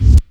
Kicks
Hot Kick.wav